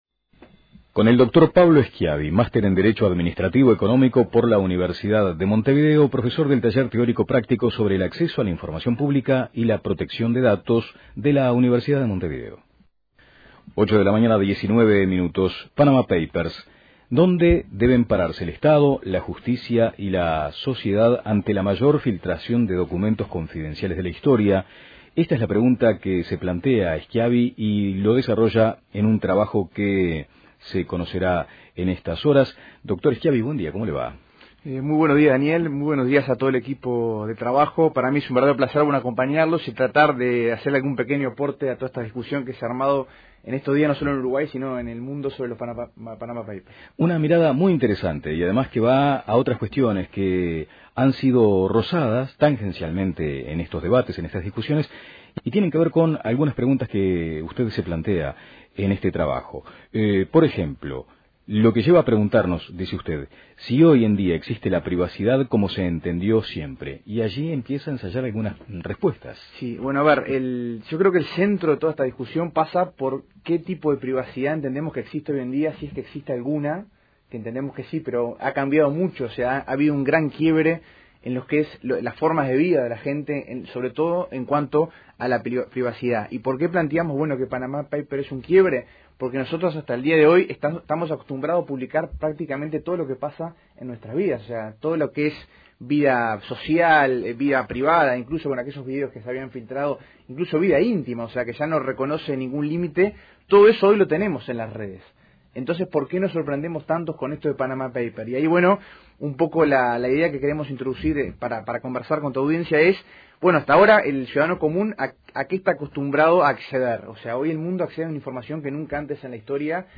Escuche la entrevista completa aquí: Descargar Audio no soportado